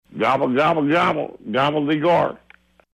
Category: Radio   Right: Both Personal and Commercial